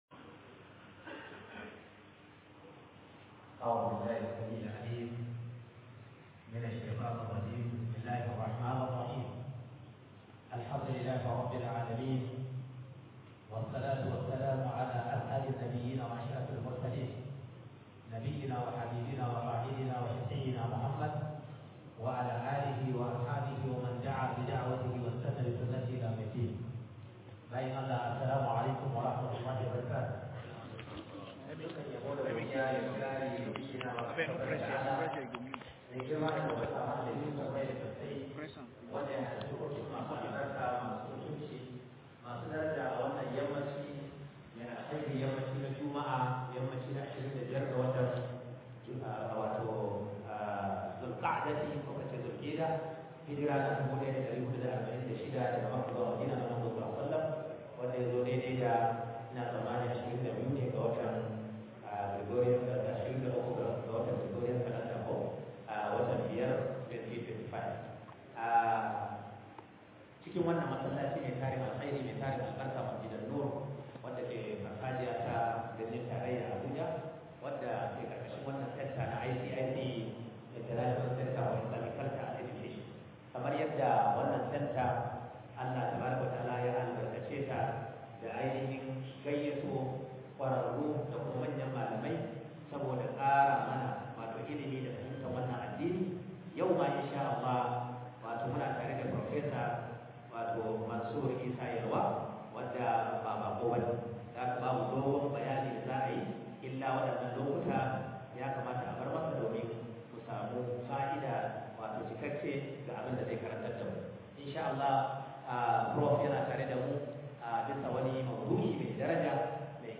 SIRIN FATIHA - MUHADARA